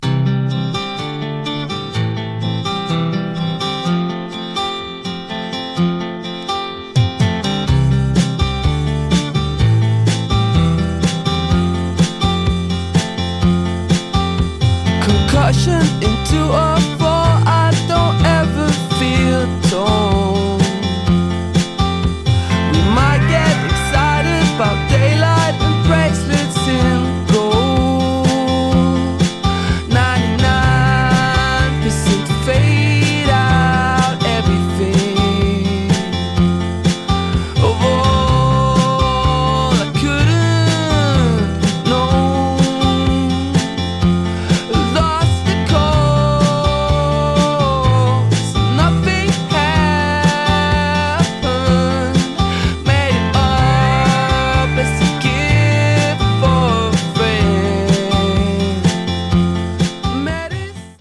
New Release Indie Rock New Wave / Rock